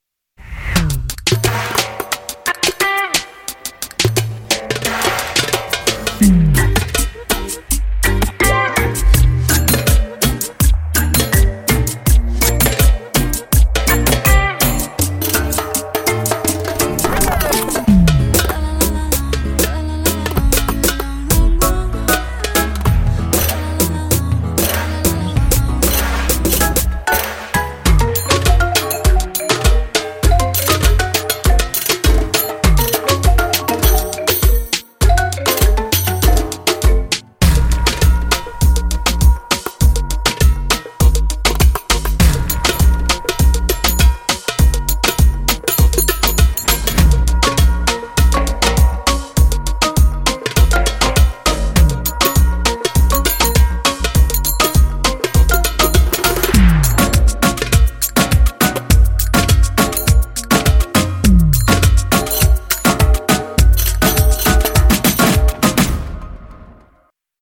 10个不同的打击乐组和60多种不同的打击乐。
更有用和更强大的迷笛律动（包括超过1100个） 包括新一代和流行音乐风格。